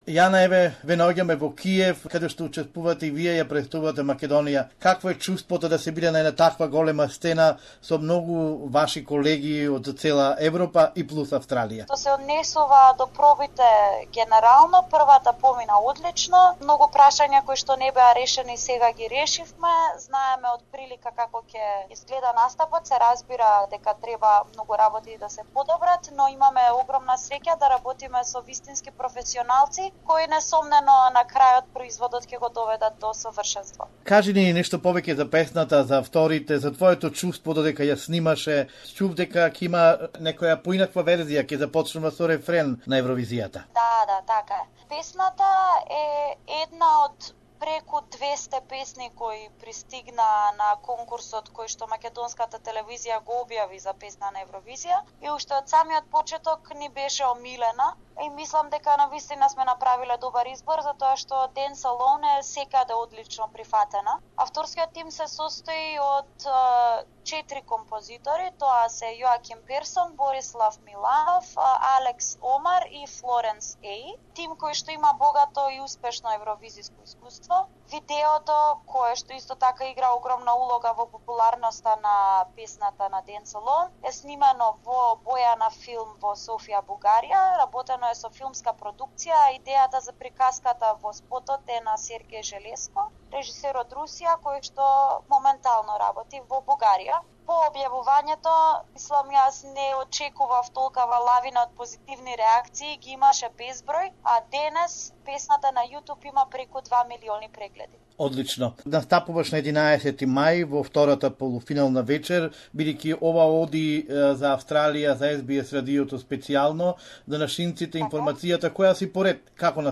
In an exclusive interview for SBS Macedonian, Jana Burceska says she's overwhelmed with the positive response for 'Dance Alone'